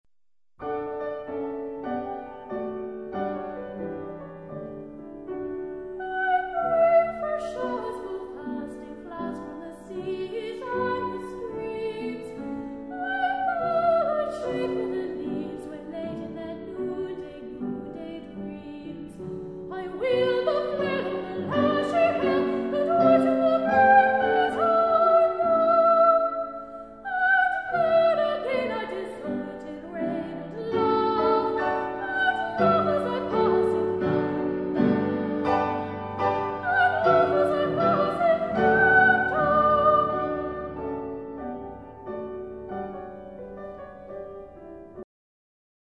Unison